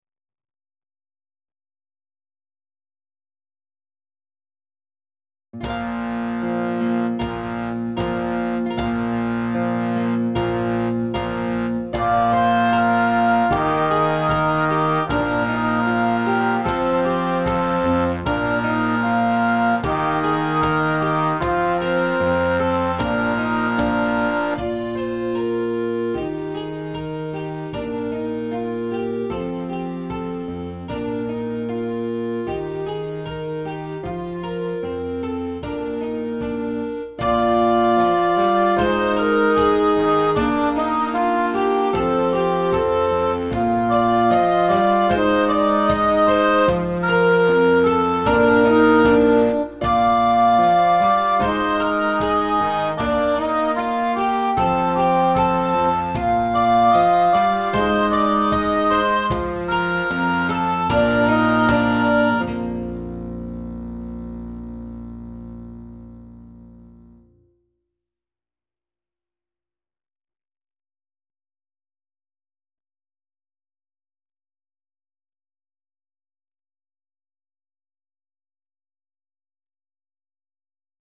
29 Mattachins (Backing Track)
An episode by Simon Balle Music